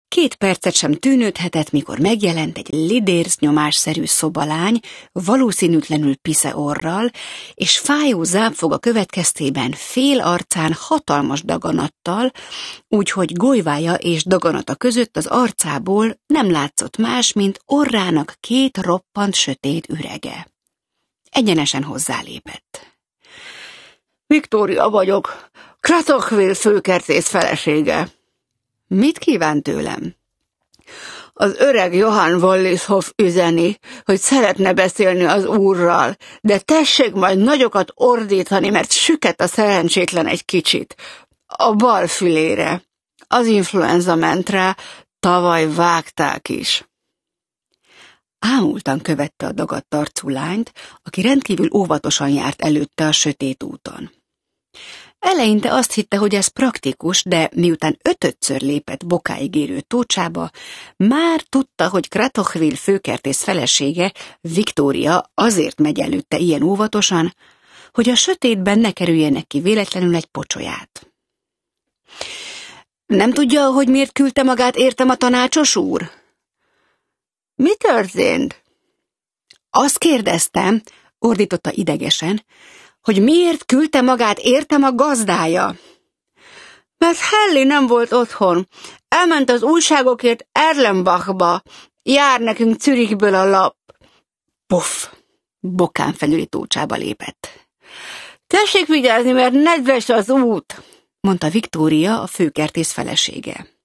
A hangoskönyv Ónodi Eszter előadásában hallható.
A szőke ciklon (Online hangoskönyv) Ónodi Eszter előadásában Rejtő Jenő (P. Howard) Hallgass bele!